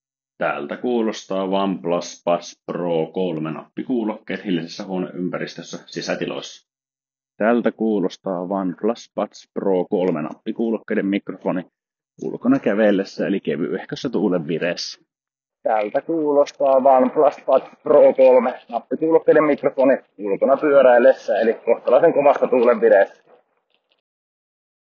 Mikrofonin ääniesimerkki
Mikrofonit OnePlus Buds Pro 3:ssa ovat sisätiloissa peruslaadukkaat äänen ollessa selkeä, joskin yläpäävoittoinen. Ulkoilmassa ääni on kävelynopeuksissa jopa erinomainen ja pyöräillessäkin tuulenvaimennus hoitaa tehtävänsä moitteetta päästäen läpi vain pientä rapinaa.
OnePlus-Buds-Pro-3-mikrofonit.wav